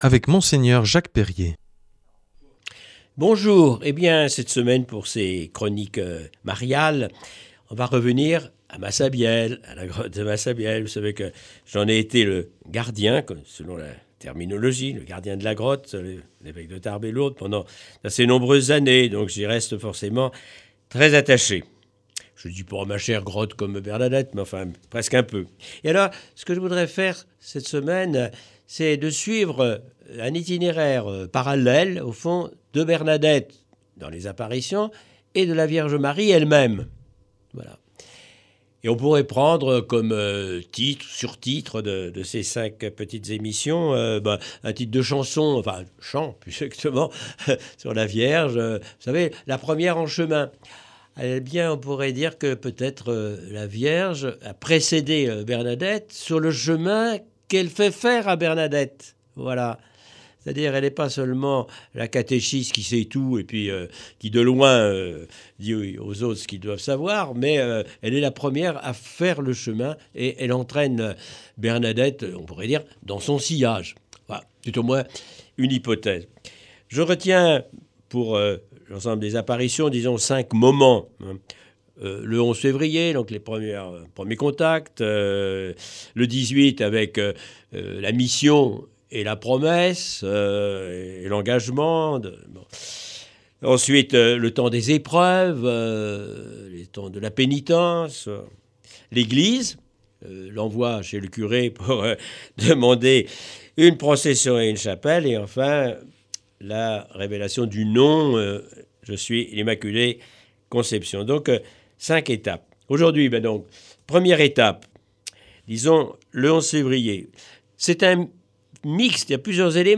L’enseignement marial de cette semaine nous est proposé par Mgr Jacques Perrier. Il nous amène à faire un parallèle entre l’itinéraire de Bernadette Soubirous à travers les apparitions à Lourdes et la vie de la Vierge Marie.